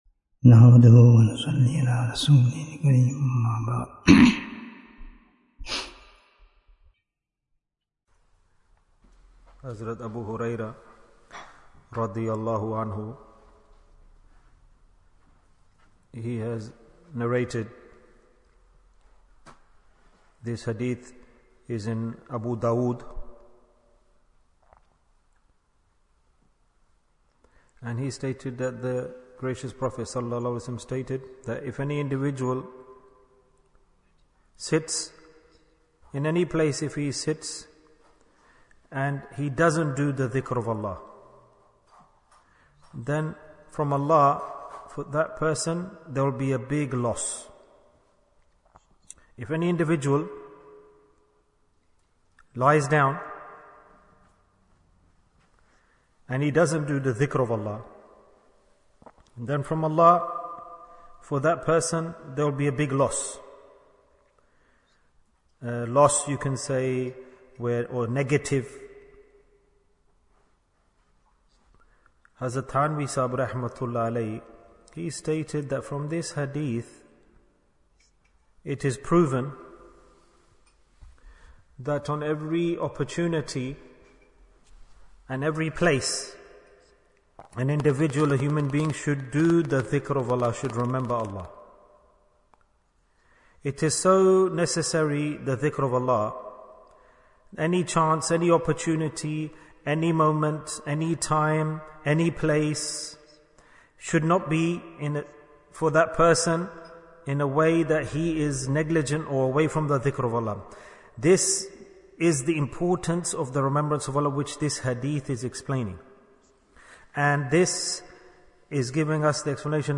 Bayan, 22 minutes30th May, 2023